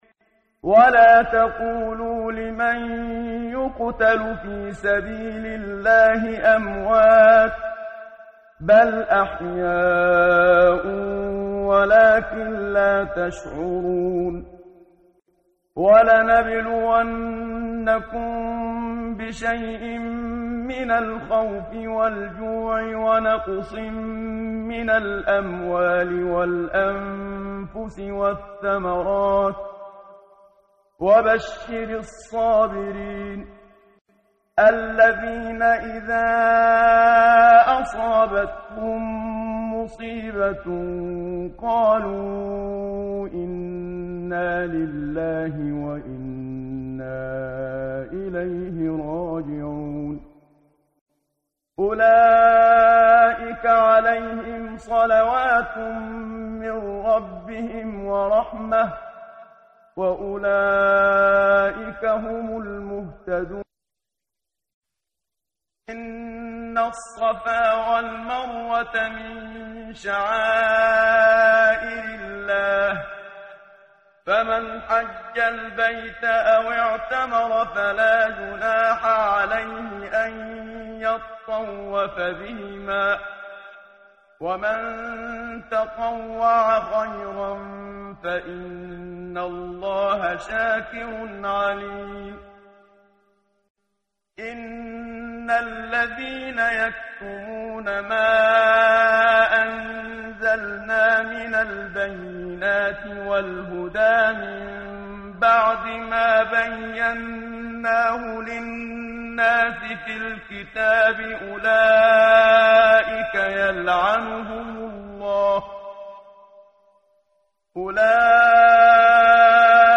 ترتیل صفحه 24 سوره مبارکه بقره (جزء دوم) از سری مجموعه صفحه ای از نور با صدای استاد محمد صدیق منشاوی
quran-menshavi-p024.mp3